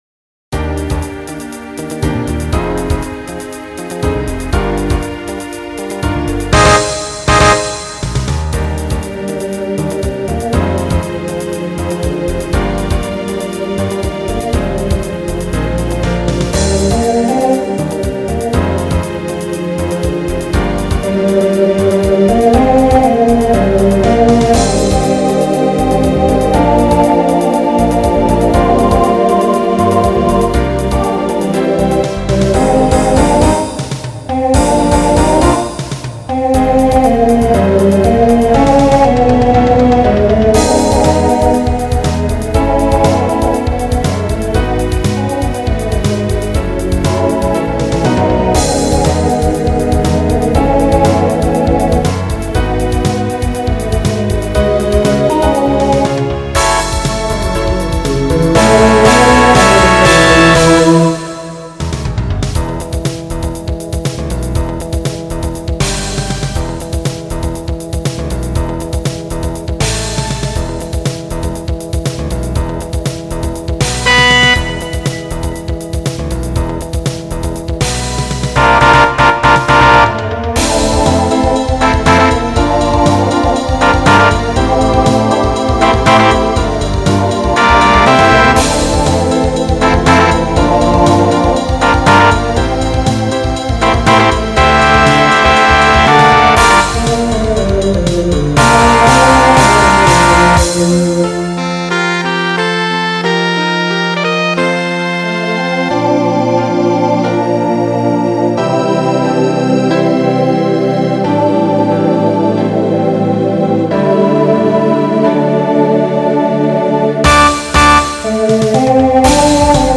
Voicing TTB Instrumental combo Genre Broadway/Film